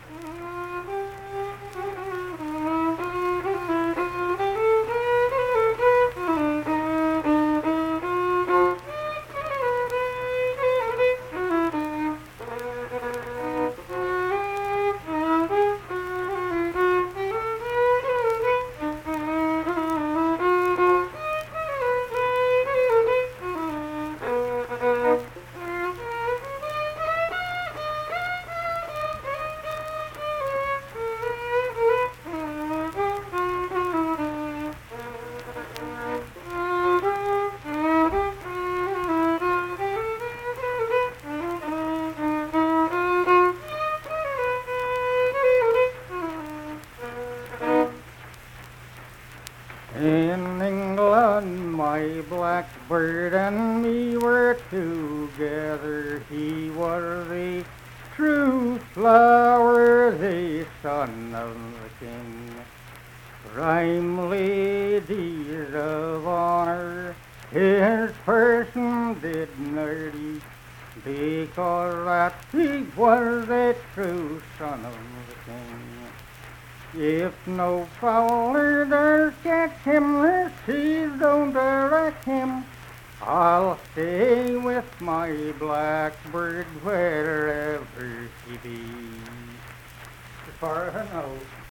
Unaccompanied vocal and fiddle music
Verse-refrain 2(1).
Instrumental Music, Love and Lovers
Voice (sung), Fiddle
Pleasants County (W. Va.), Saint Marys (W. Va.)